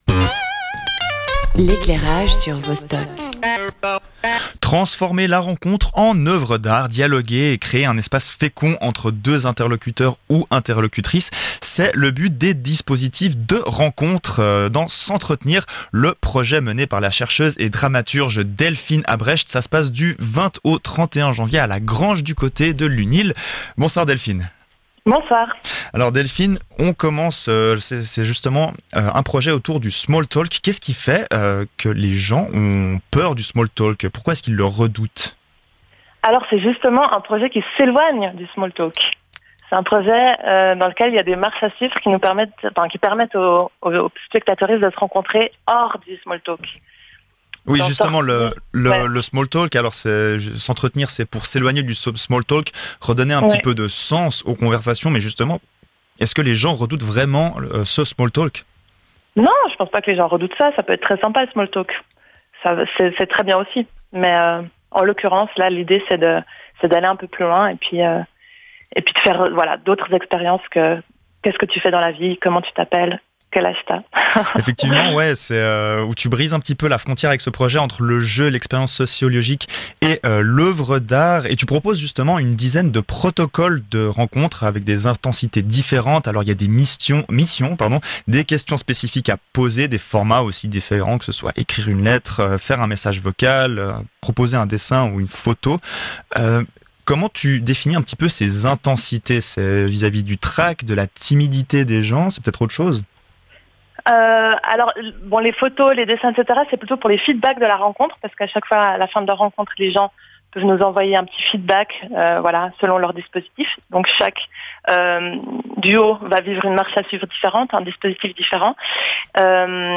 S’entretenir